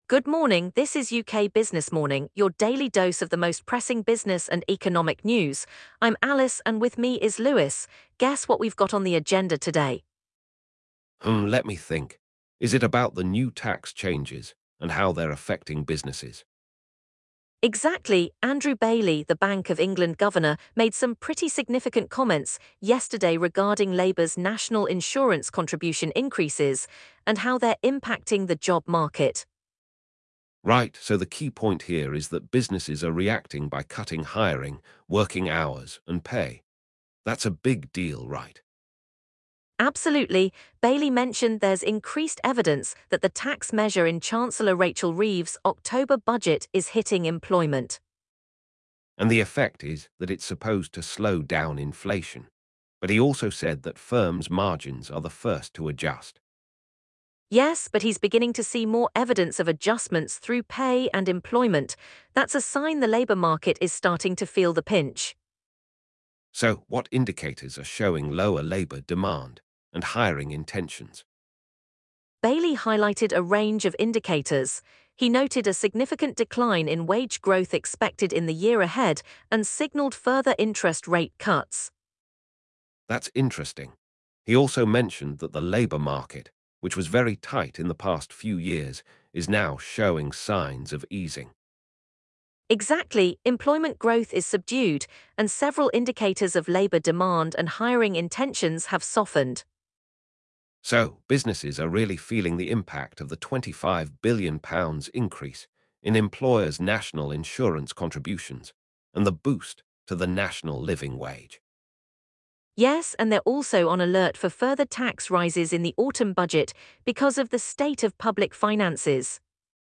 UK morning business news
The Bank of England Governor highlights how businesses are adjusting by cutting hiring, working hours, and pay, while also signaling potential interest rate cuts. The hosts explore the signs of an easing labor market, the effects of public finances on future tax rises, and the Bank's cautious approach to tackling inflation.